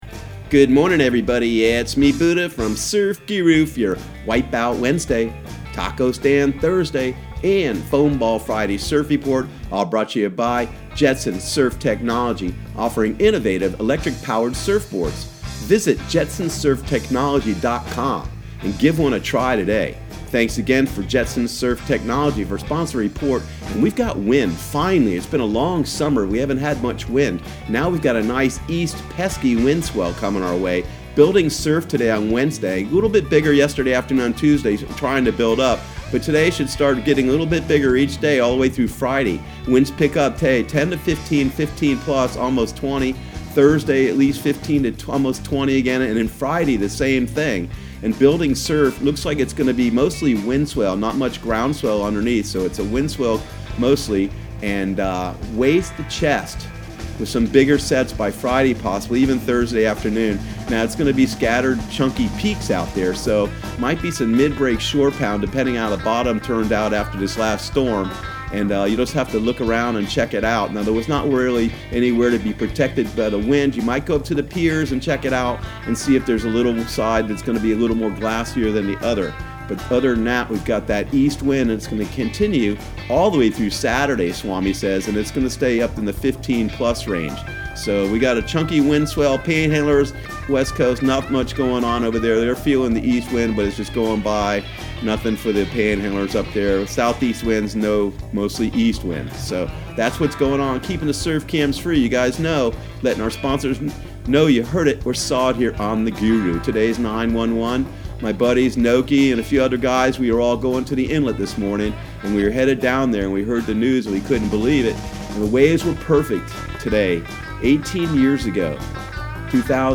Surf Guru Surf Report and Forecast 09/11/2019 Audio surf report and surf forecast on September 11 for Central Florida and the Southeast.